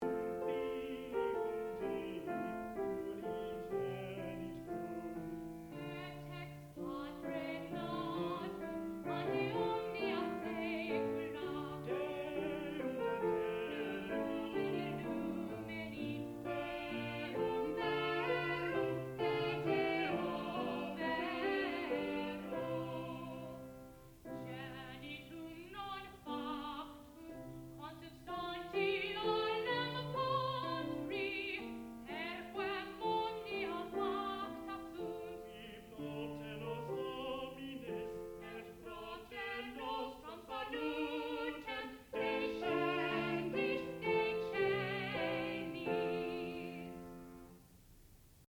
sound recording-musical
classical music
piano
tenor